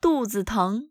dùzi téng
ドゥ ズー トン